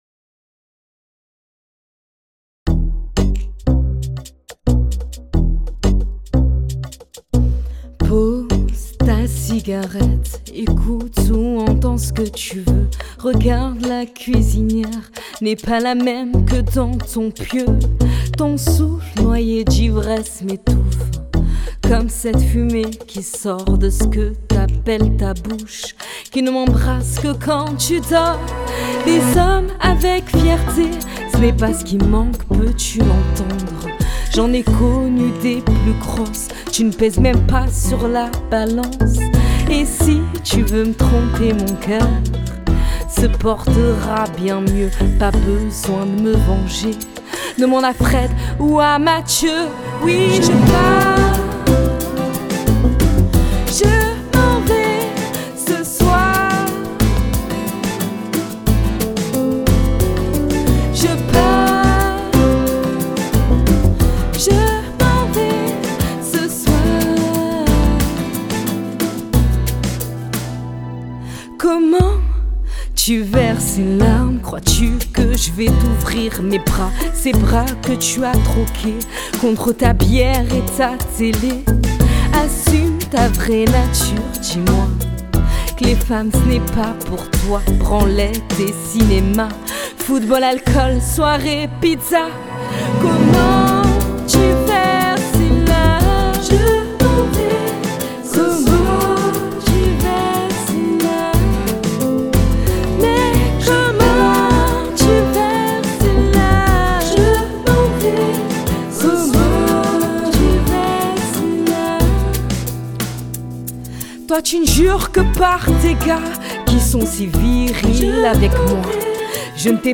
Style musical : variété française.